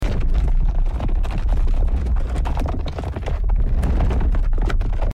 sound_earthquake.2c1a9858..mp3